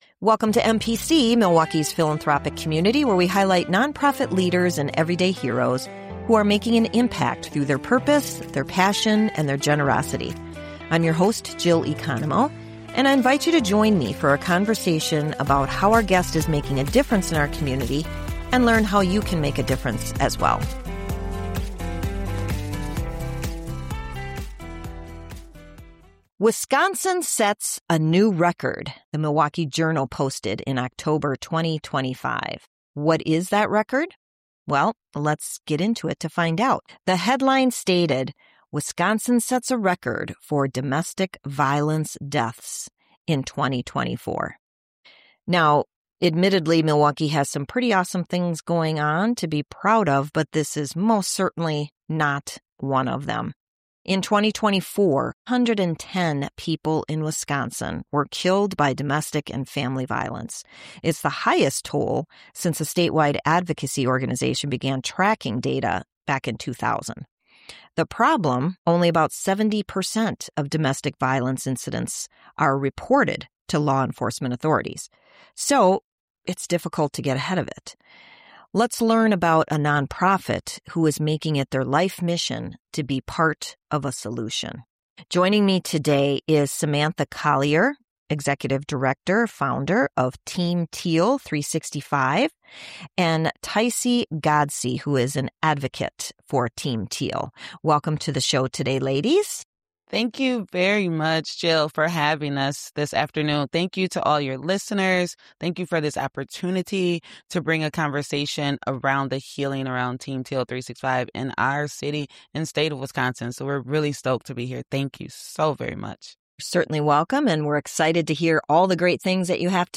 Milwaukee's Philanthropic Community airs every Sunday at 10am on WISN AM 1130.
Guests include leaders of local non-profit organizations as well as individuals that have been inspired to create change.